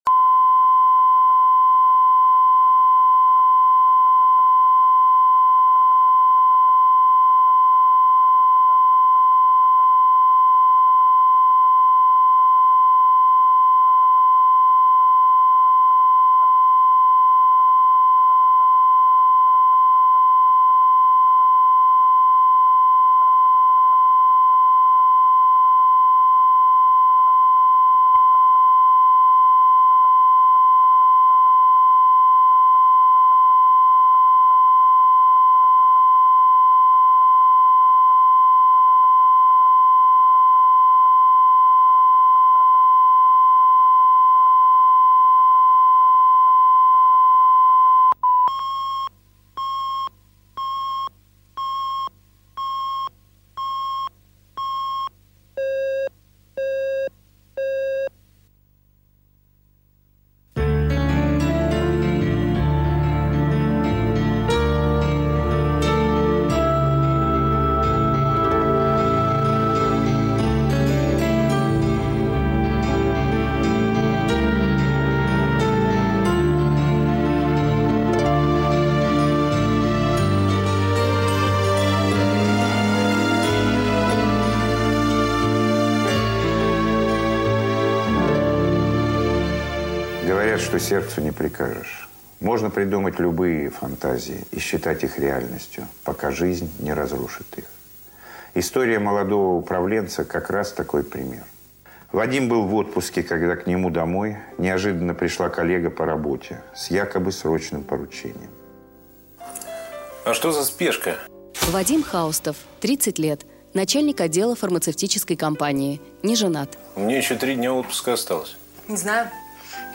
Aудиокнига Девочка по вызову Автор Александр Левин.